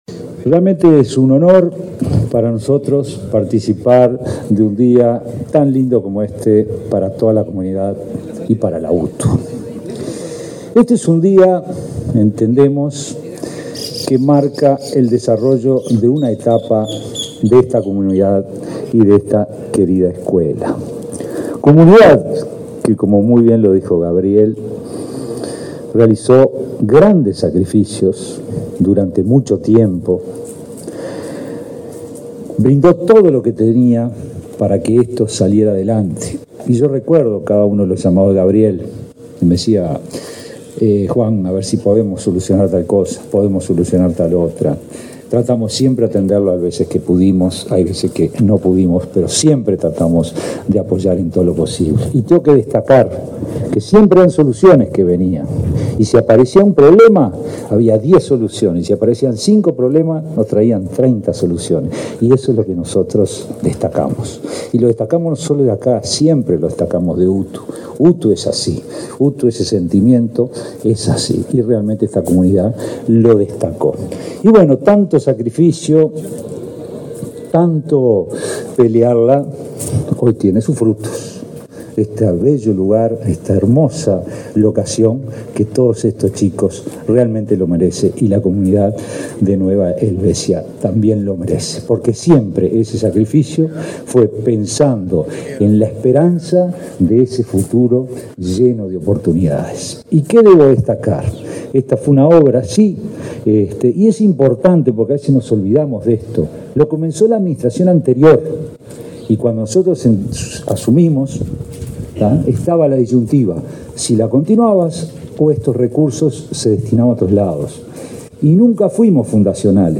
Palabras del director general de UTU, Juan Pereyra
Este viernes 20, el director general de la UTU, Juan Pereyra, participó en la inauguración de la reforma de la escuela técnica de Nueva Helvecia, en